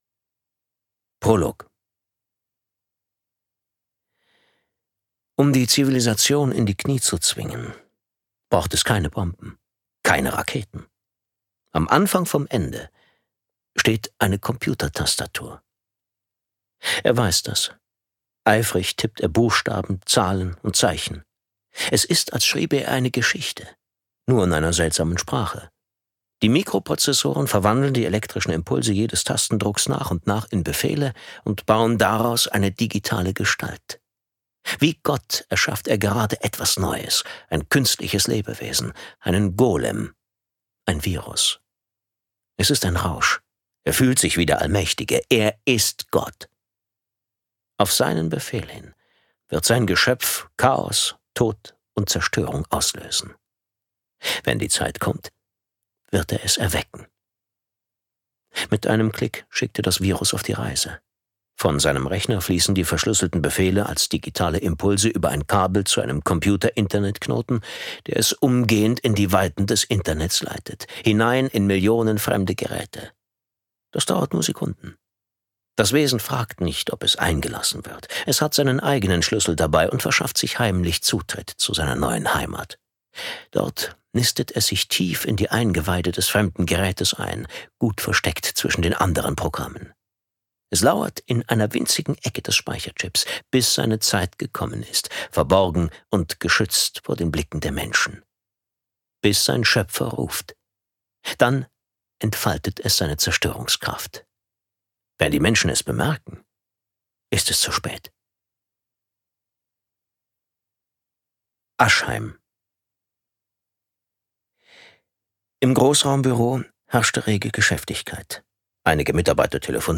Autorisierte Lesefassung